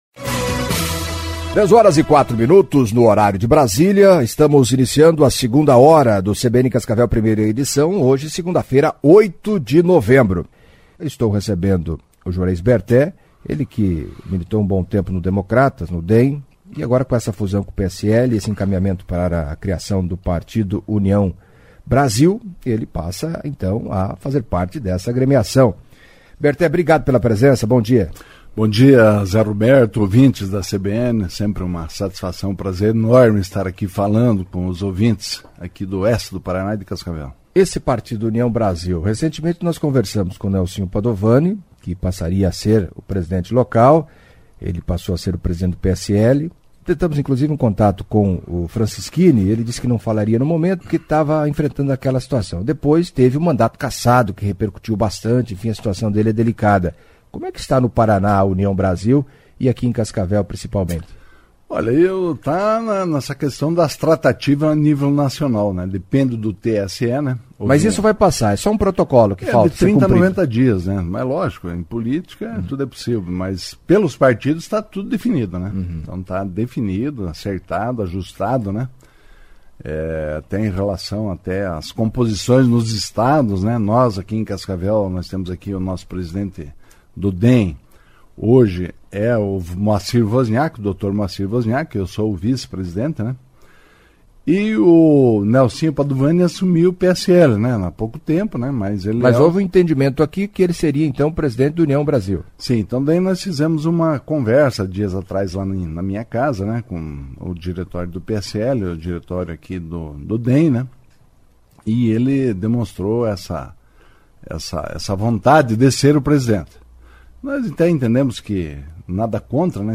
em entrevista à CBN Cascavel nesta segunda-feira (08) destacou a importância da nova sigla e diz que pretende disputar uma cadeira na Assembleia Legislativa.